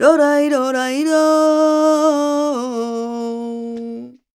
46a02voc-a.aif